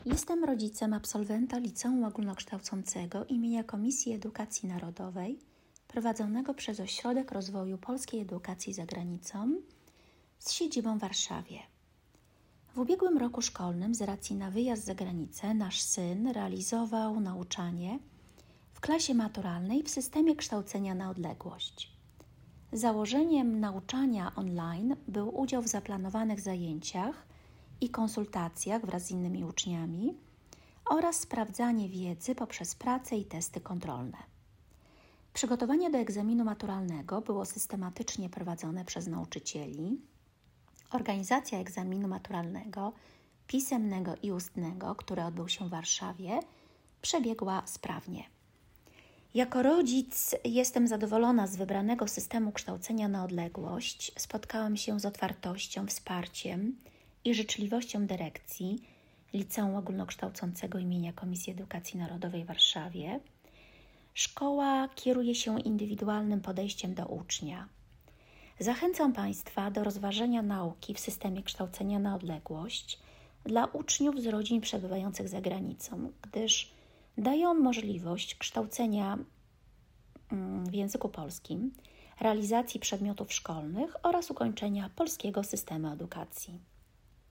1. Rekomendacja rodzica ucznia KNO.